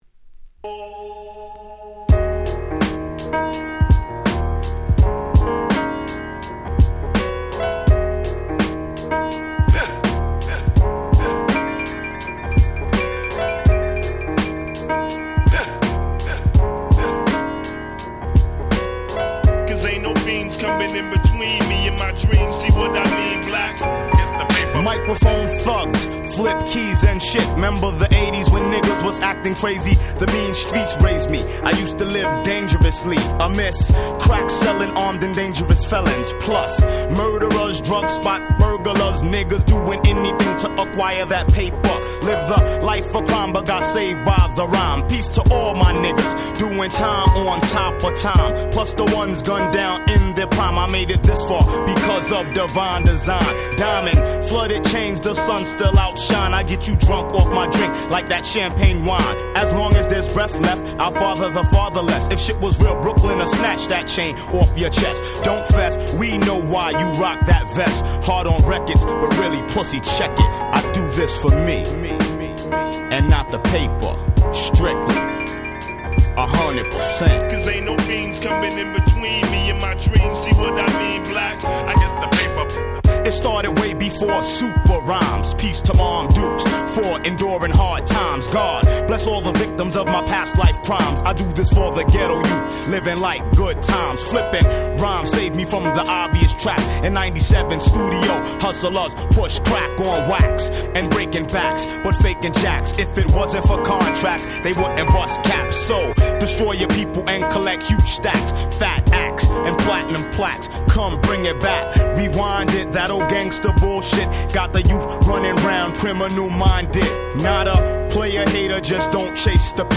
90's Hip Hop Classics !!